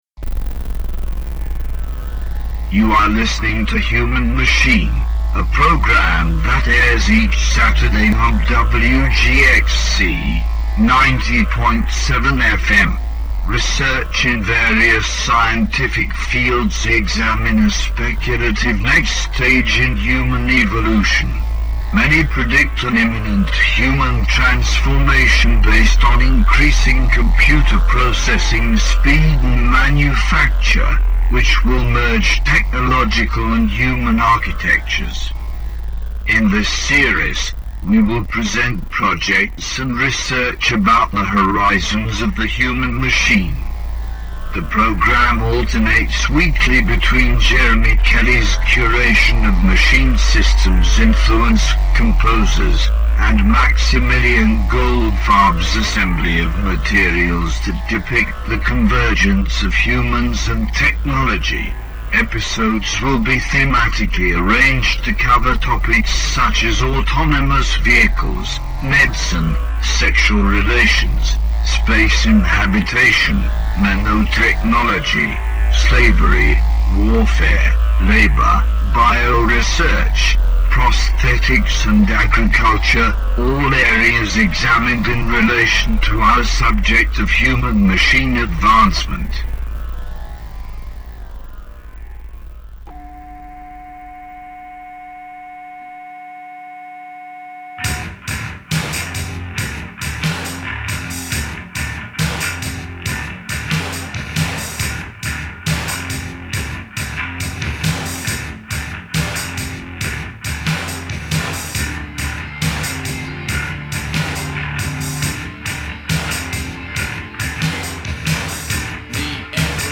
Human Machine is a hour-long radio broadcast produ...